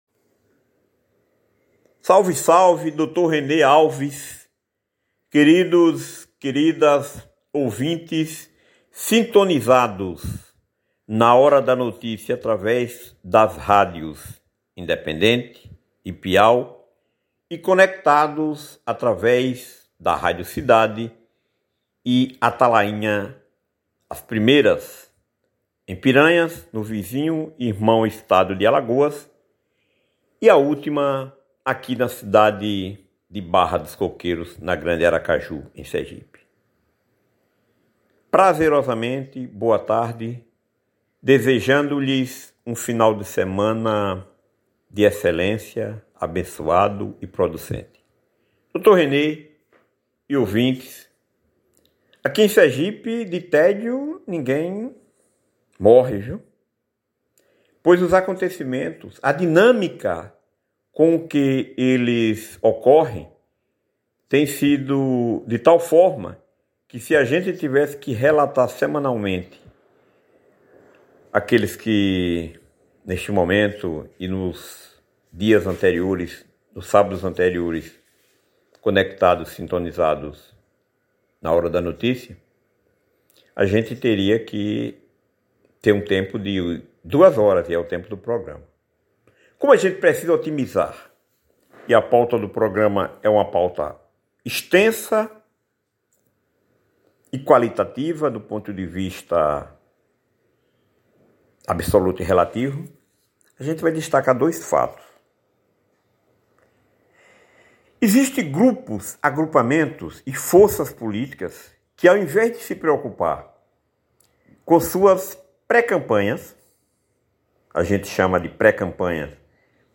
Levada ao ar na tarde deste sábado, 02, das 12 às 14 horas